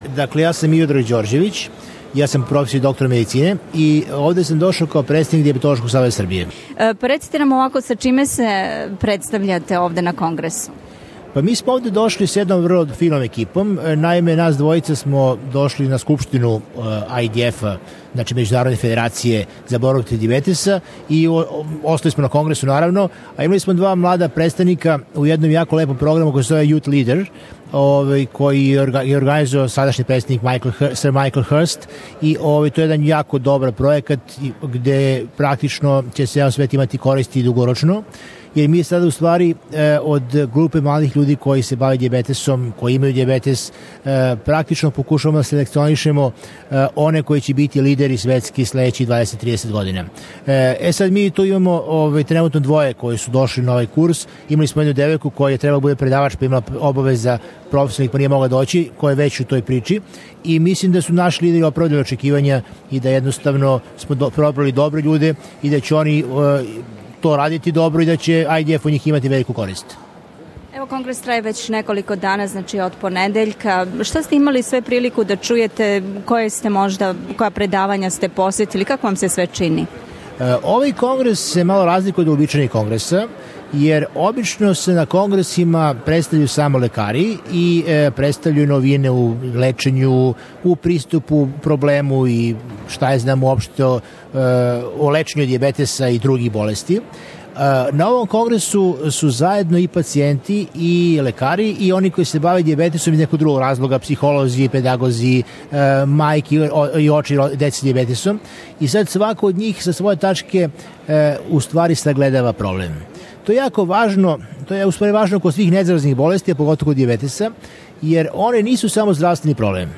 Са њим смо разговарали у Конгресном центру у Мелбурну 5. децембра.